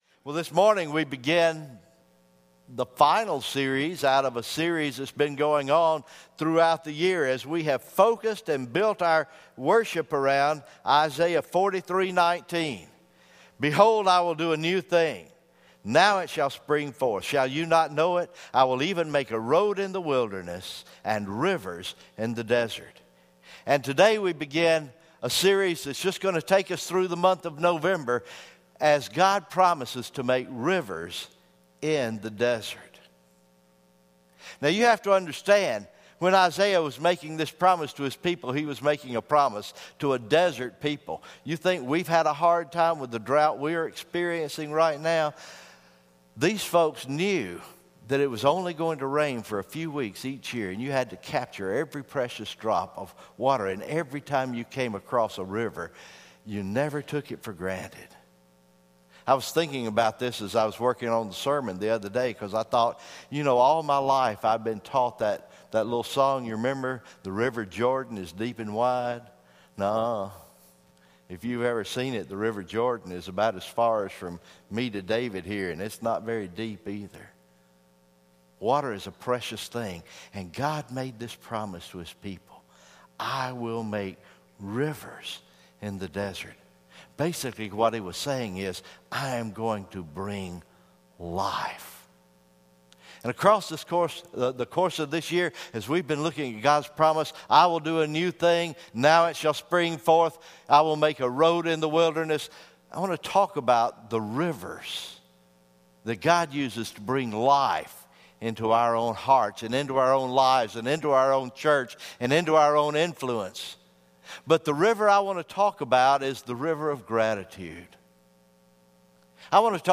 November 13, 2016 Morning Worship